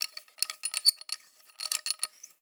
SFX_Repair_01.wav